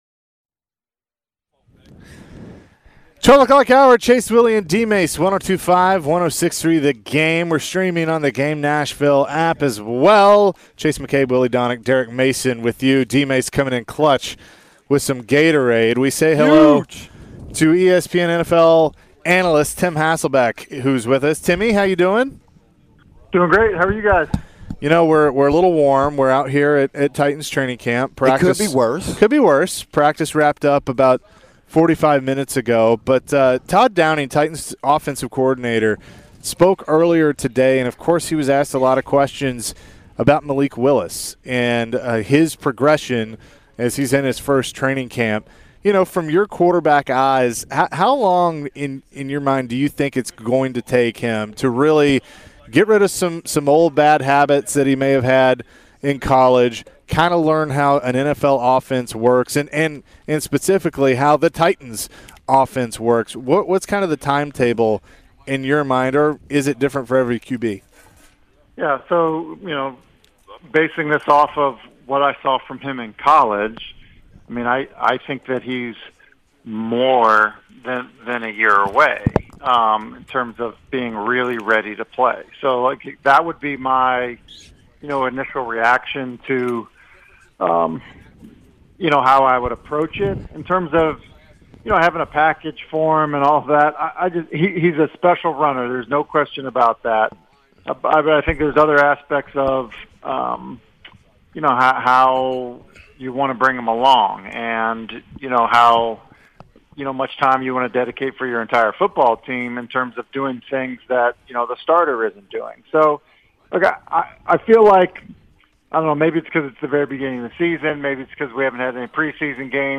Tim Hasselbeck Full Interview (08-02-22)
The guys talk to ESPN NFL Analyst Tim Hasselbeck on all things NFL, including who will win the AFC South division and how well did the NFL handle the Deshaun Watson ruling?